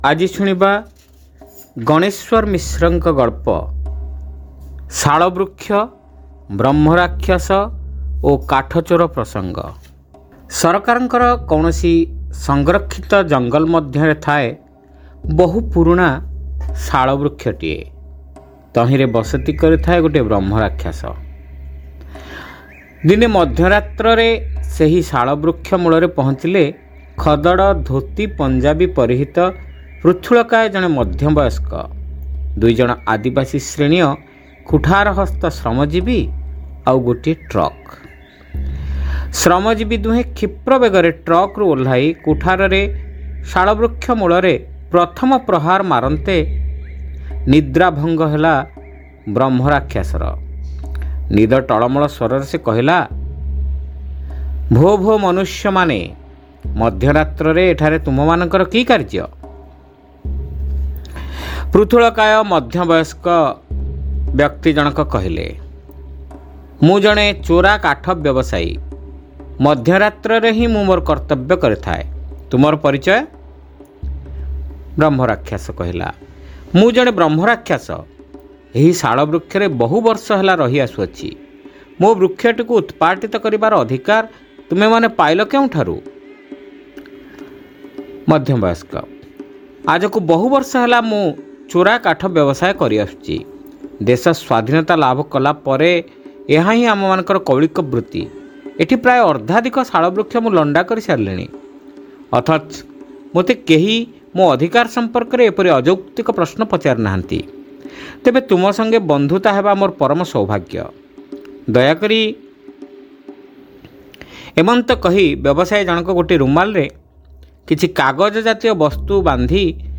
Audio Story : Shalabrukshya Brahmarakshyasa o Kathachora Prasanga